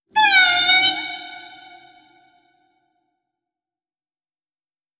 khloCritter_Male23-Verb.wav